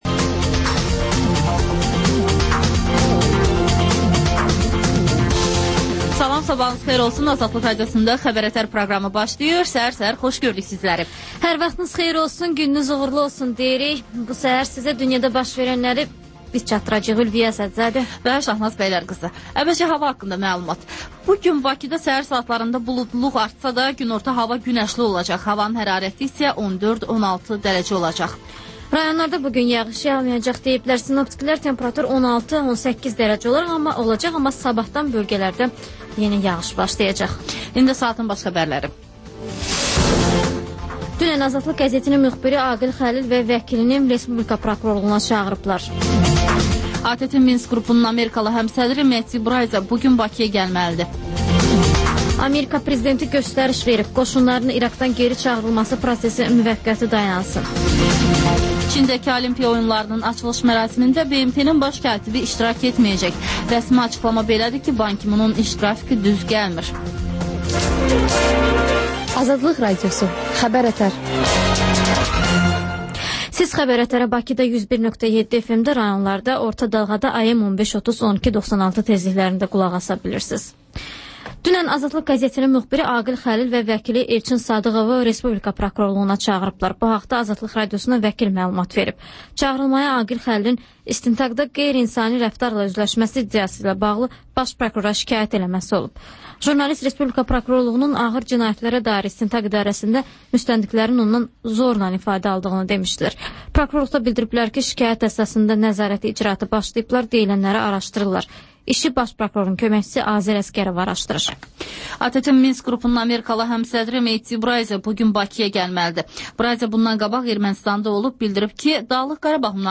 Xəbər-ətər: xəbərlər, müsahibələr və TANINMIŞLAR verilişi: Ölkənin tanınmış simalarıyla söhbət